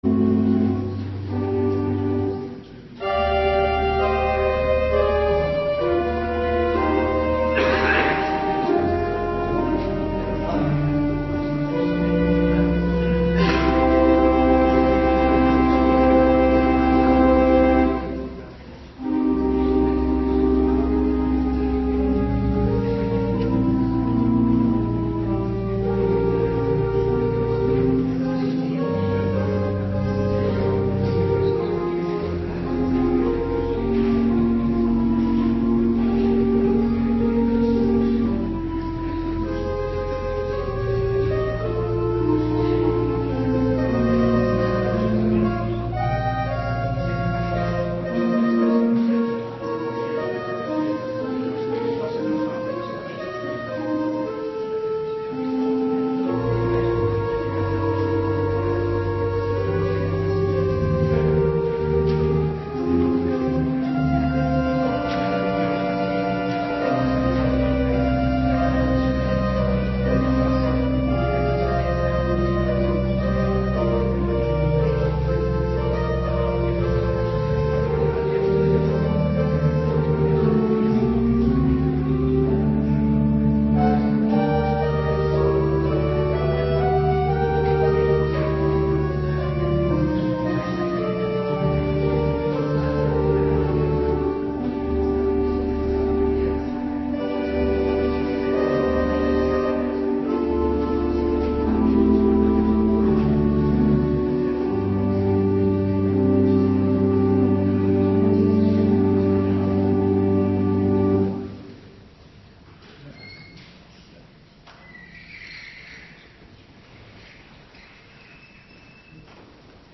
Avonddienst 2 april 2026